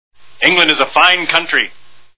A Fish Called Wanda Movie Sound Bites